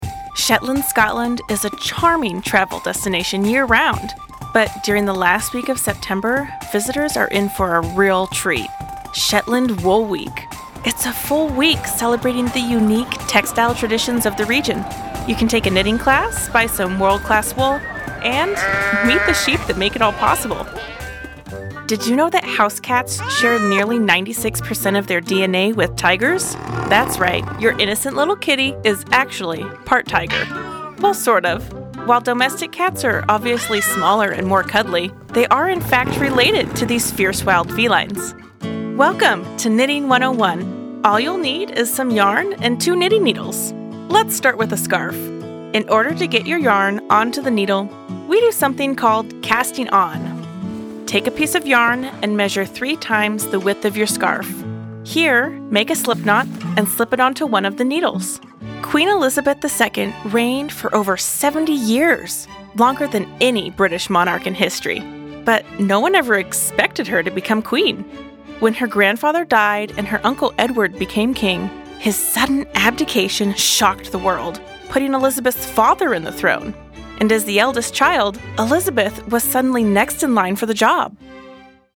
Narration Demo
Bright & Youthful Female Voice
- Audio-Technica AT2020 cardioid condenser mic with pop filter